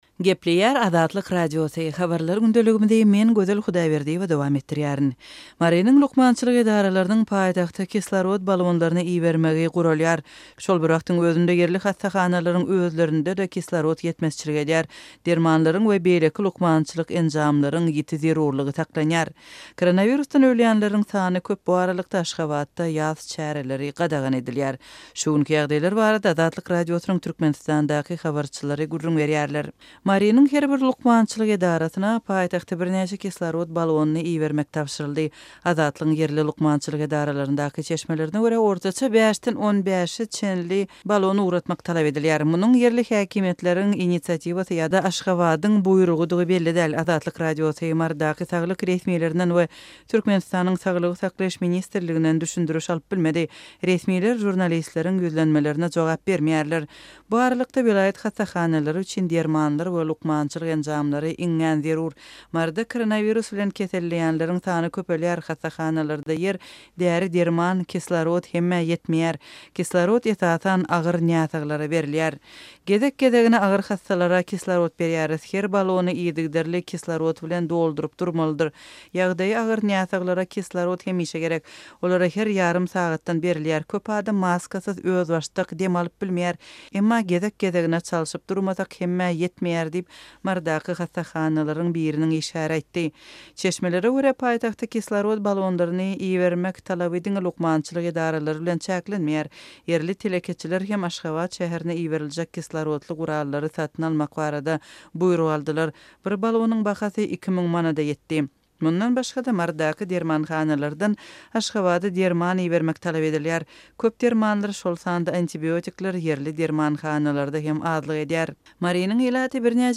Şu günki ýagdaýar barada Azatlyk Radiosynyň Türkmenistandaky habarçylary gürrüň berýär.